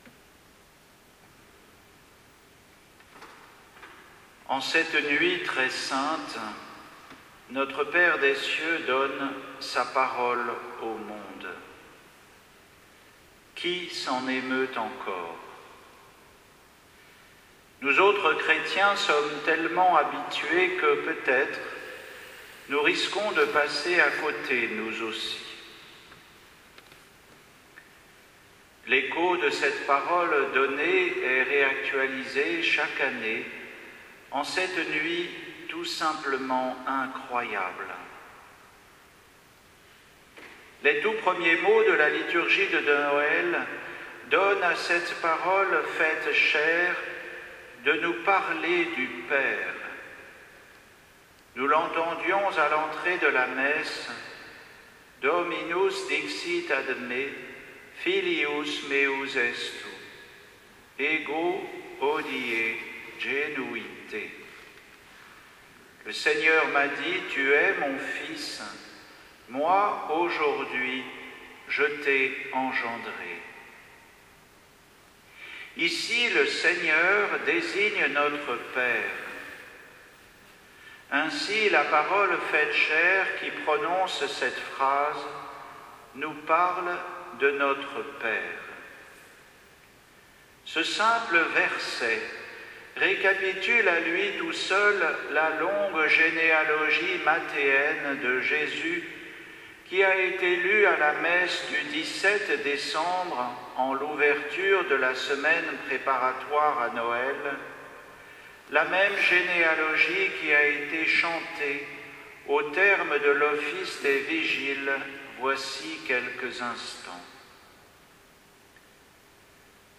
Homélie de la Nuit de Noël 2021